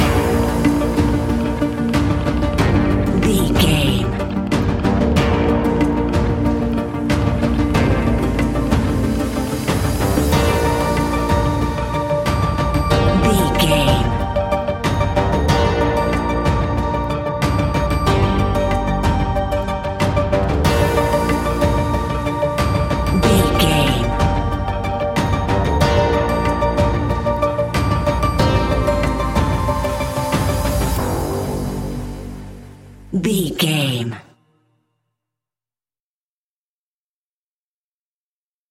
Aeolian/Minor
scary
tension
ominous
dark
eerie
synthesiser
drums
strings
ticking
electronic music
electronic instrumentals